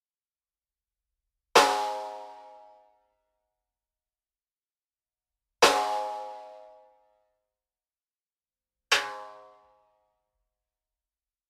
Звуки анимации
Удар Бонг